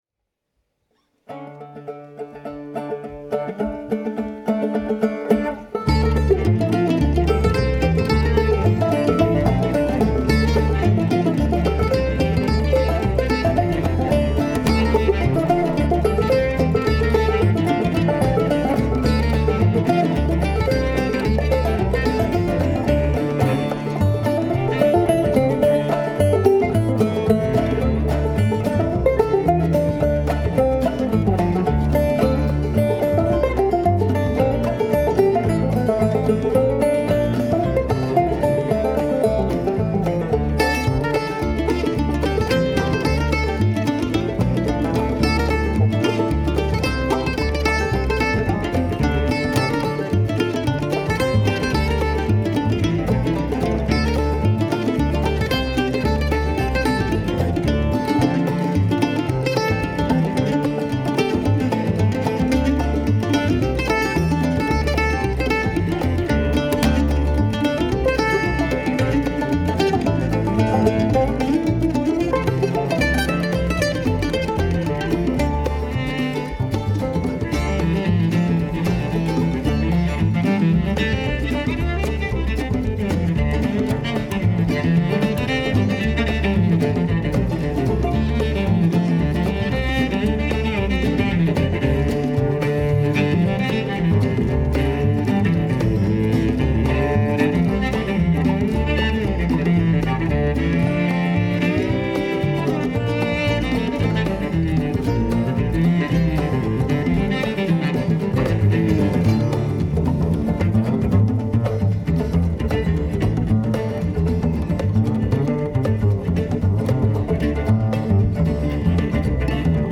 Mandolin
Banjo
Cello
Percussion